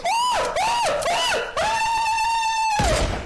yelling-6